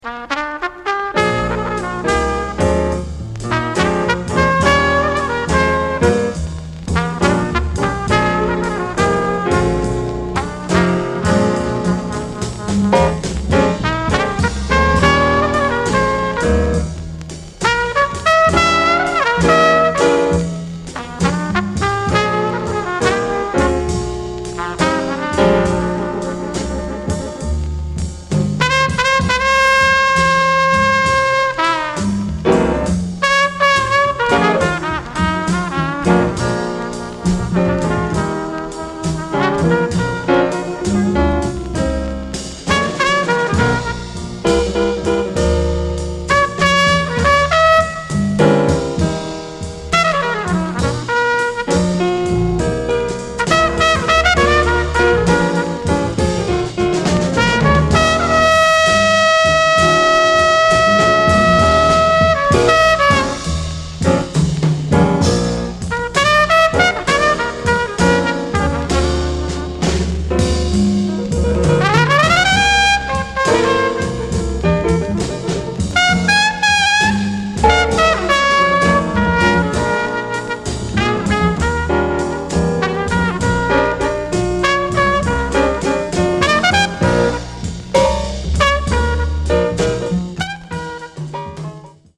The master from Jajce in quartet formation with